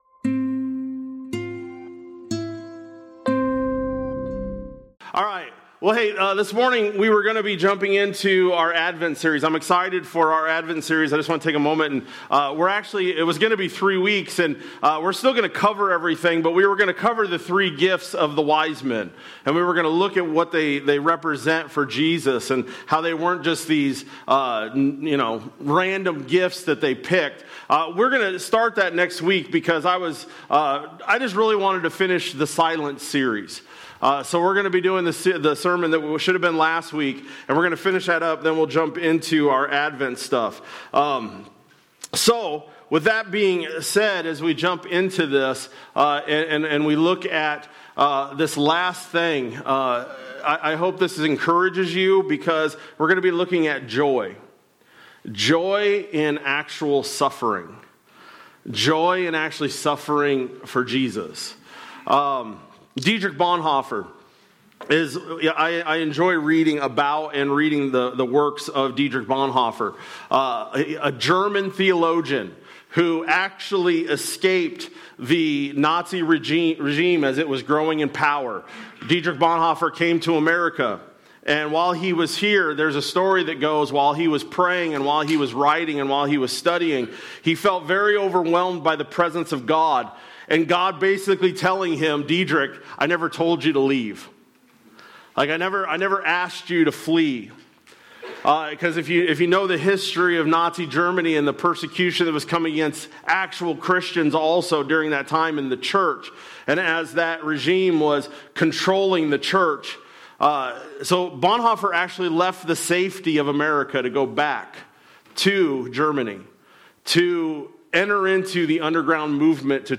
Dec-7-25-Sermon-Audio.mp3